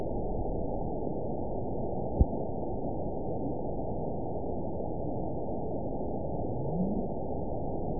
event 915393 date 11/30/22 time 20:20:29 GMT (2 years, 6 months ago) score 8.70 location INACTIVE detected by nrw target species NRW annotations +NRW Spectrogram: Frequency (kHz) vs. Time (s) audio not available .wav